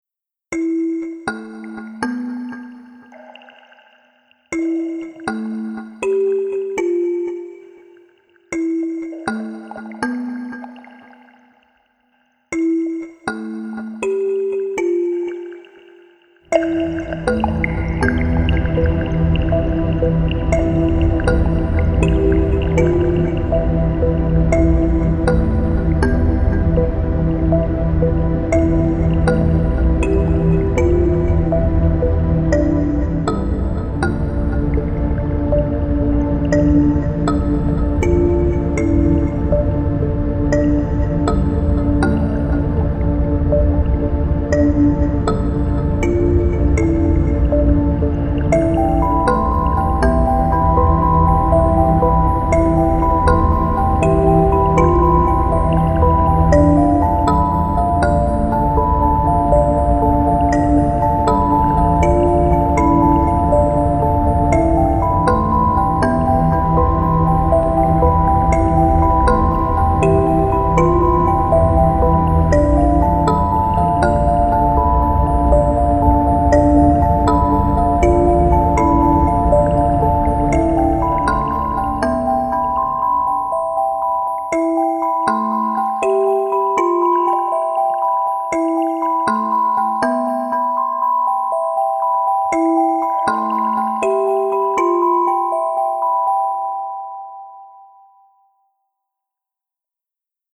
テンポ：♩=120
主な使用楽器：シンセベル、シンセパッド etc